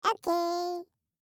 알림음 8_HobbitOkay6.mp3